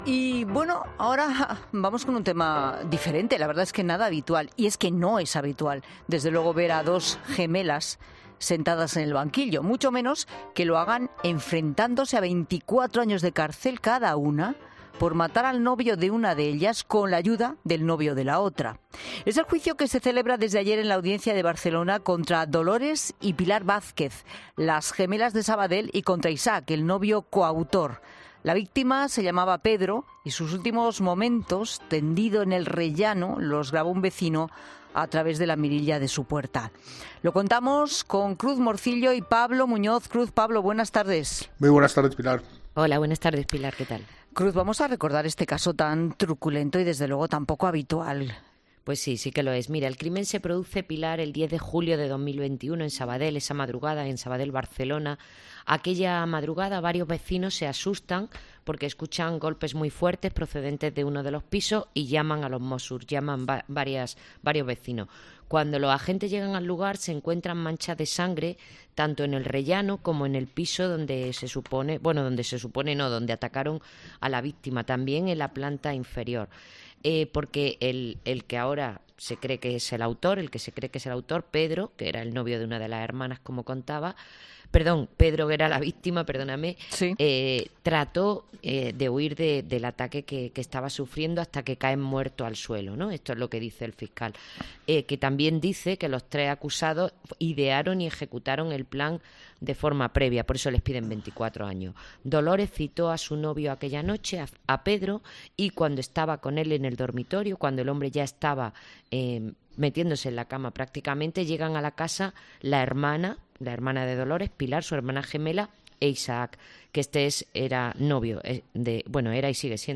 Los periodistas especializados en sucesos